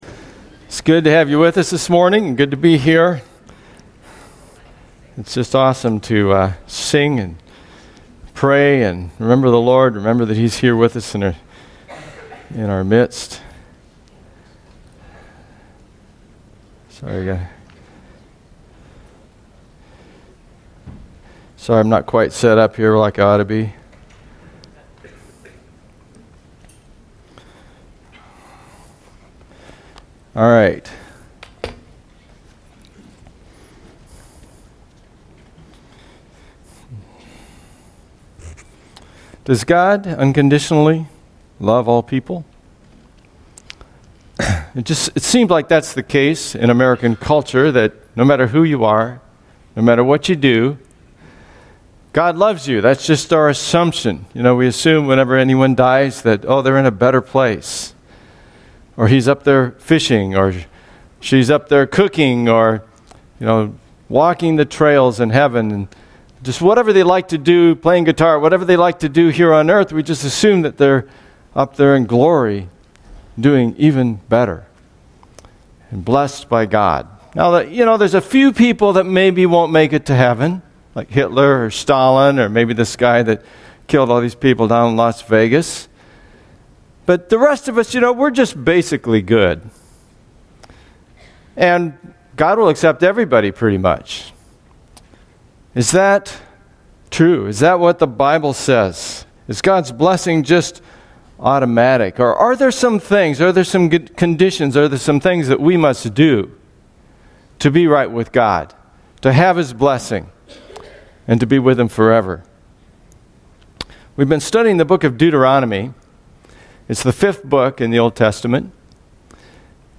2017 Stay up to date with “ Stonebrook Church Sermons Podcast ”